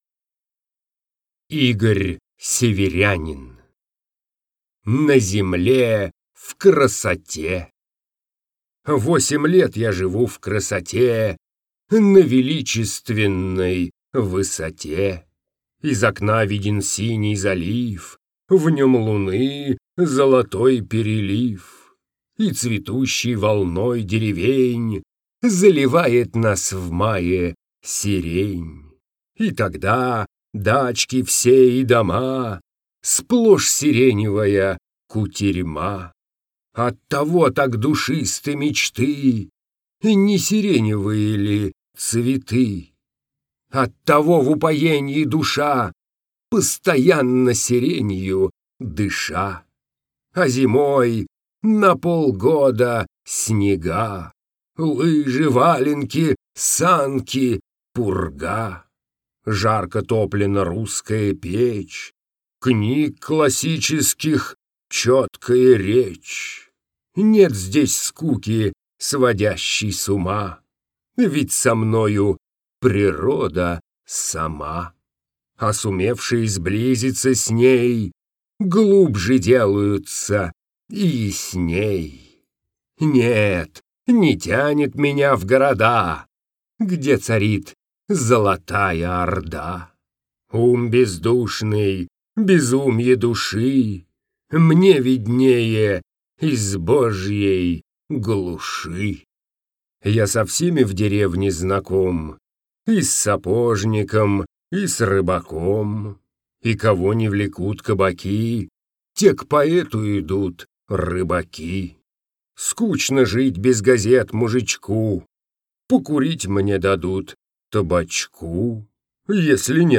1. «Игорь Северянин – На земле в красоте (Хвост читает)» /